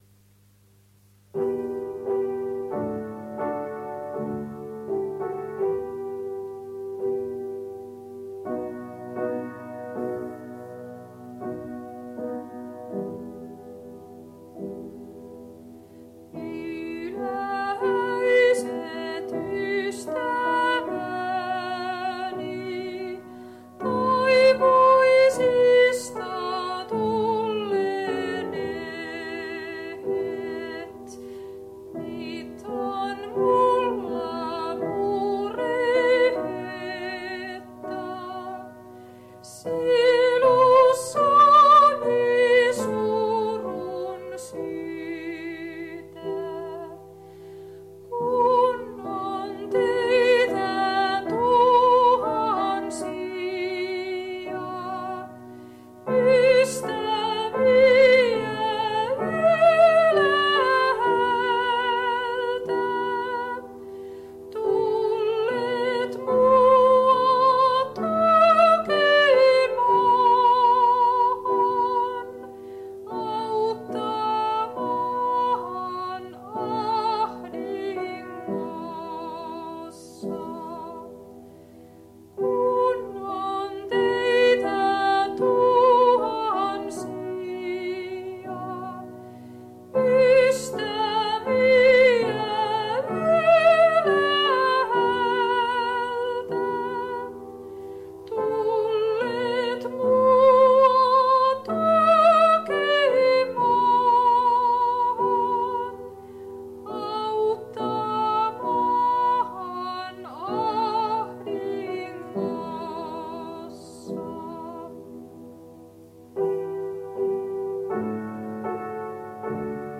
Musiikkiesityksiä; kuoro, yksinlauluja